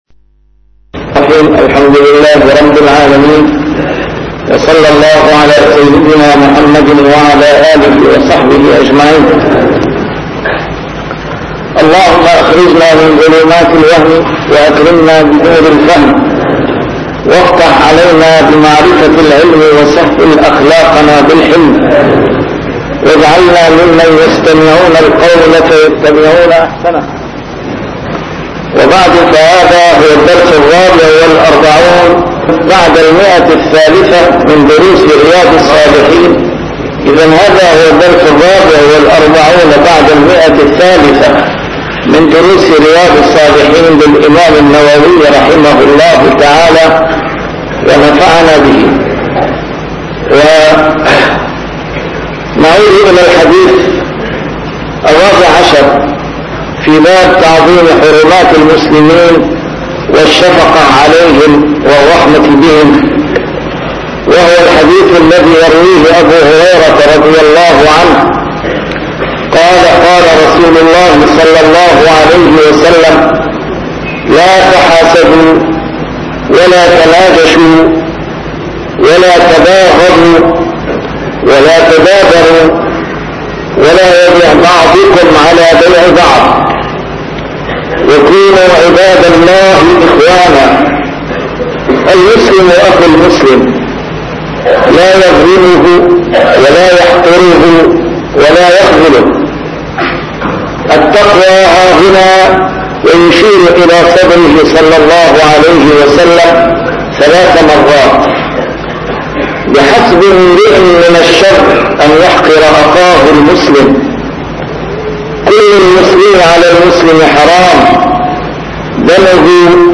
A MARTYR SCHOLAR: IMAM MUHAMMAD SAEED RAMADAN AL-BOUTI - الدروس العلمية - شرح كتاب رياض الصالحين - 344- شرح رياض الصالحين: تعظيم حرمات المسلمين